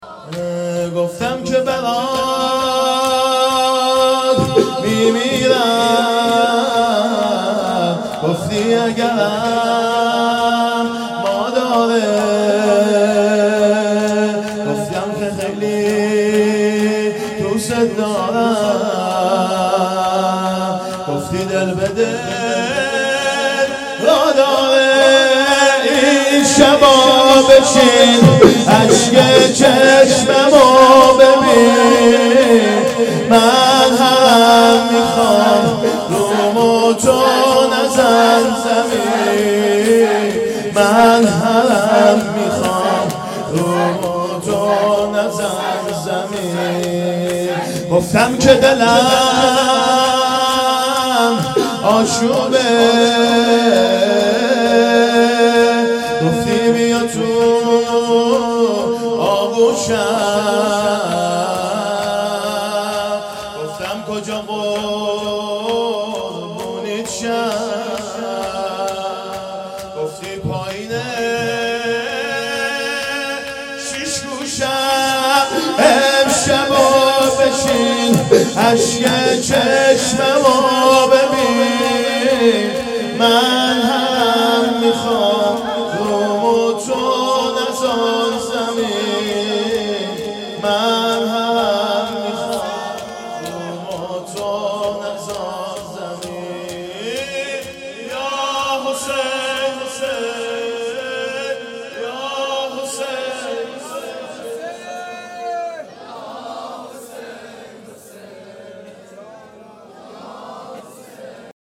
محرم 1440 _ شب پنجم